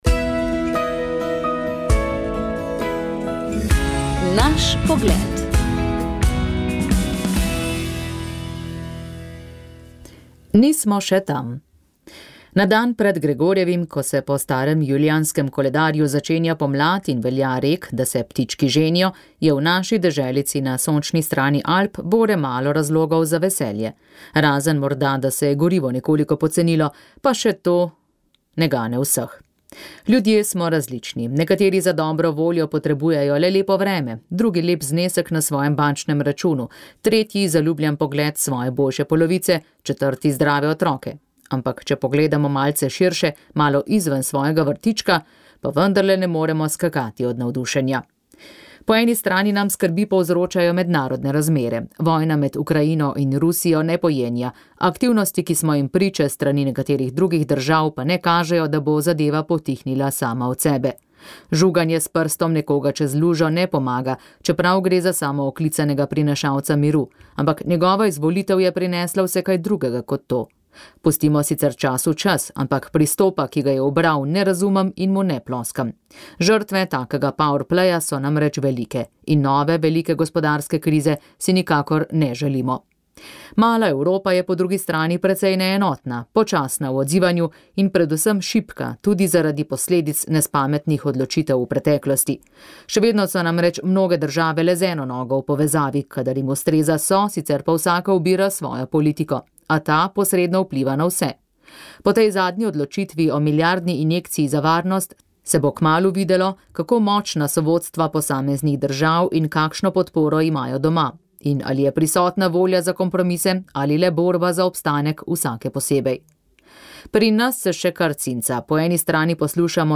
Prenos maše iz župnije Pertoča na Goričkem
sodeloval je mešani peski zbor sv.